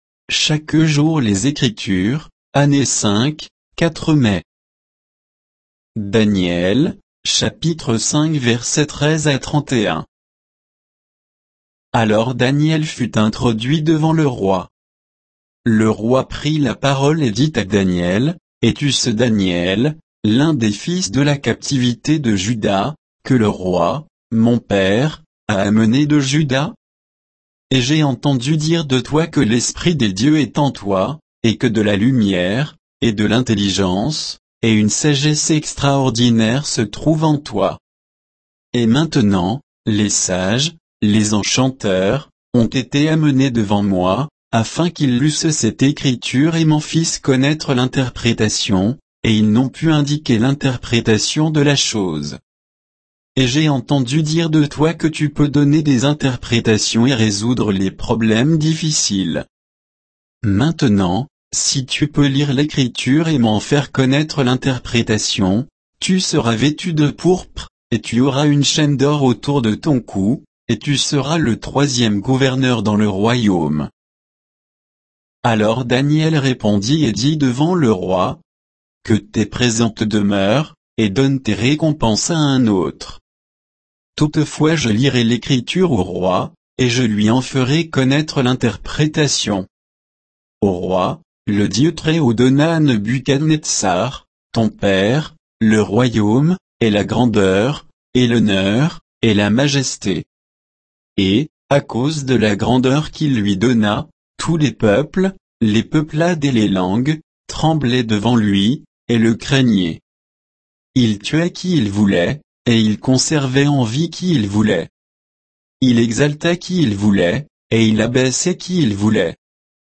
Méditation quoditienne de Chaque jour les Écritures sur Daniel 5, 13 à 31